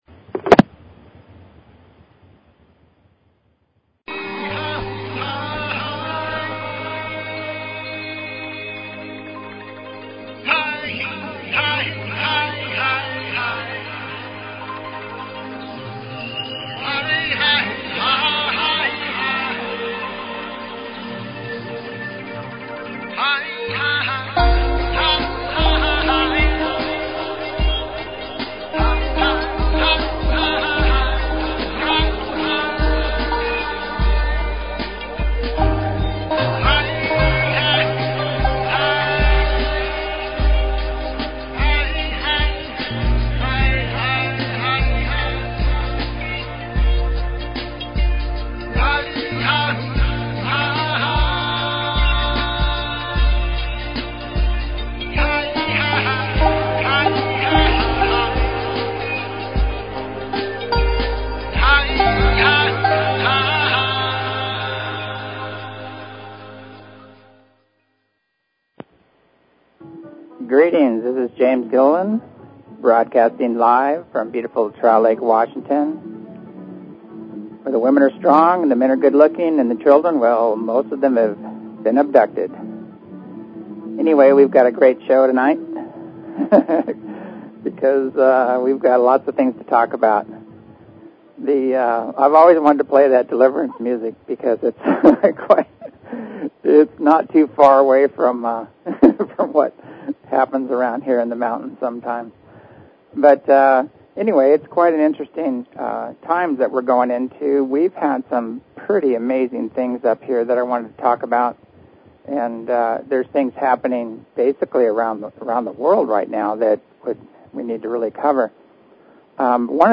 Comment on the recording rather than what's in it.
Open lines, latest events at the ranch